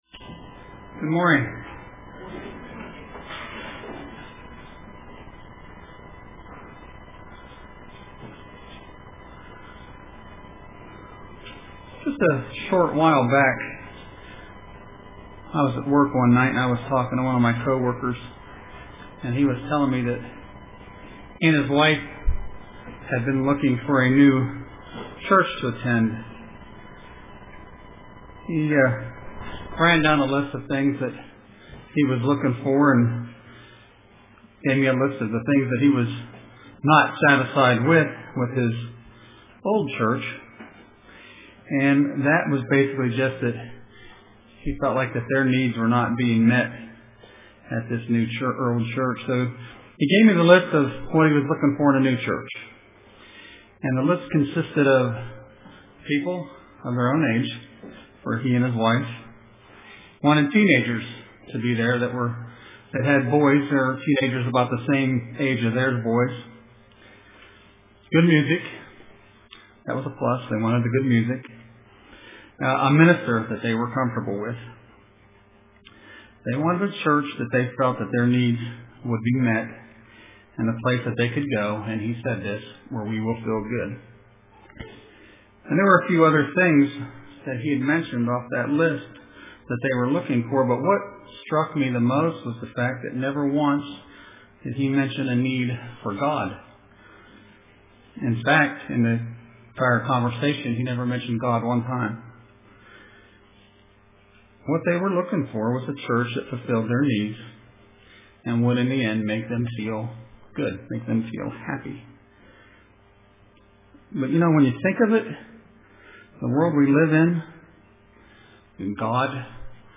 Print Peace of God UCG Sermon Studying the bible?